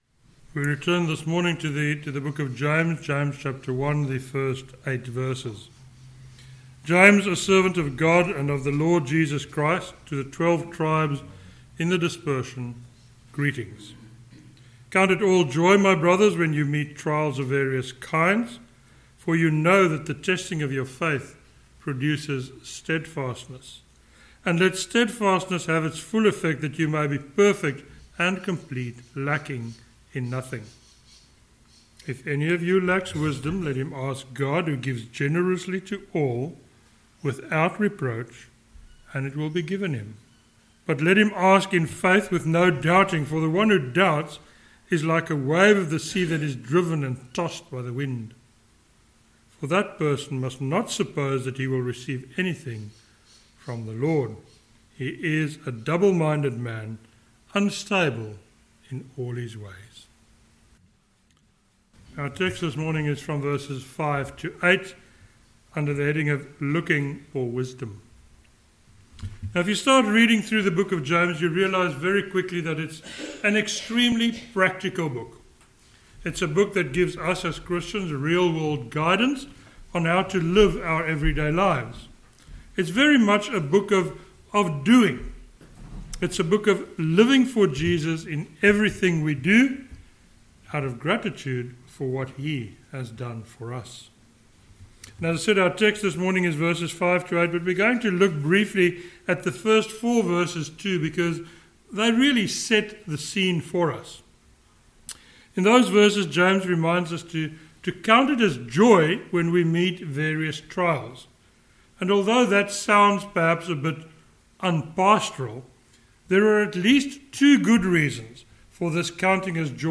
a sermon on James 1:5-8